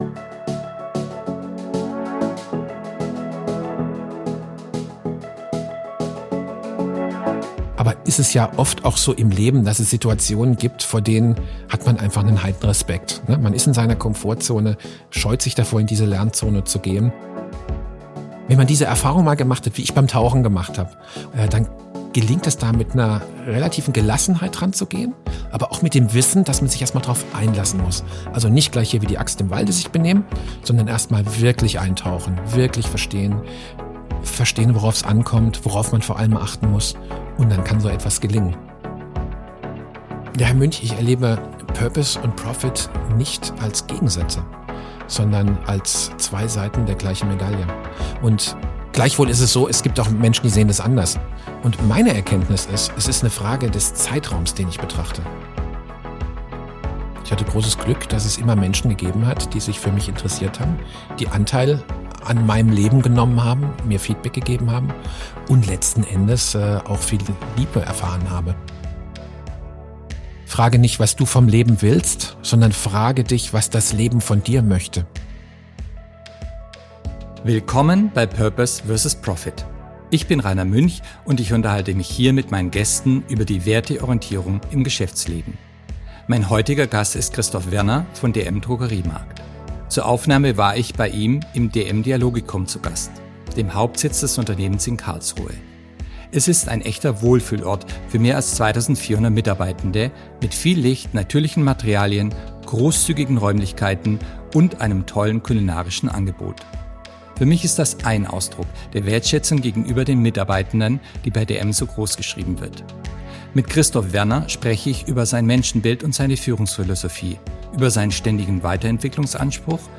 Das Gespräch wurde aufgezeichnet am 11. April 2025.